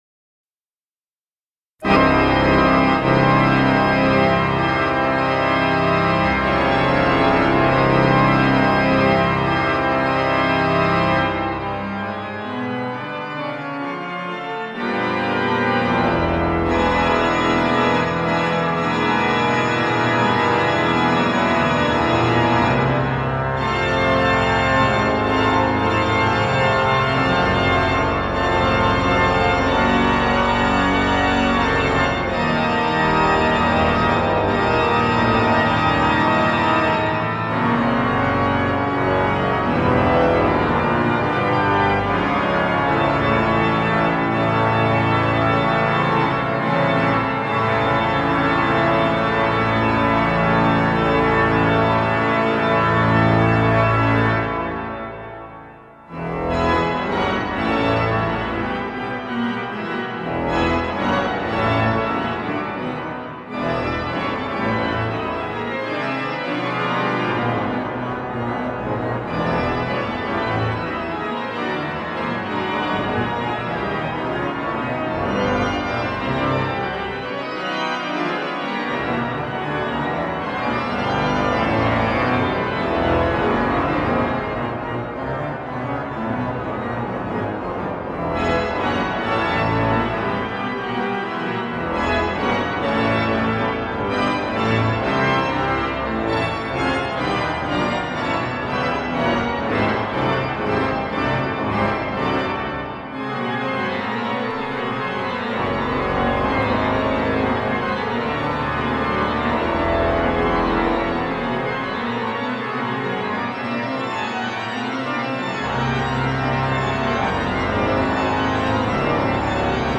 Orgel der Jesuitenkirche, Wien